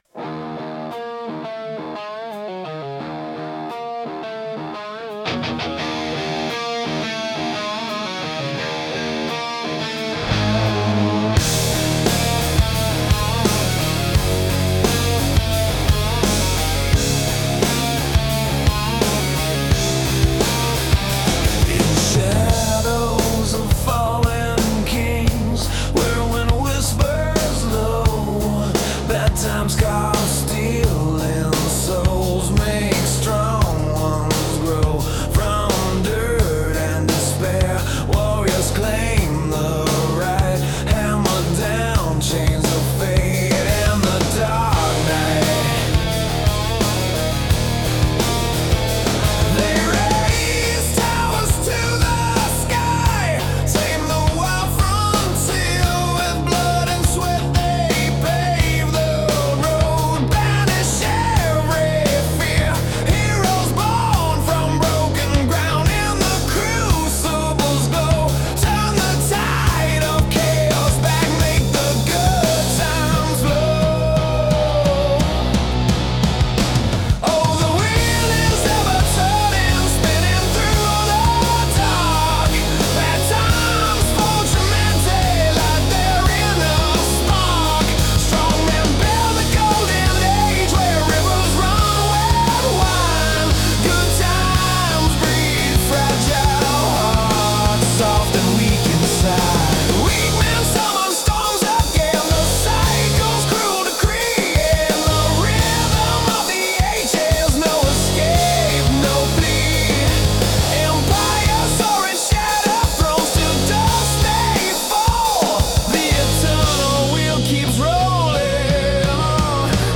It’s rock.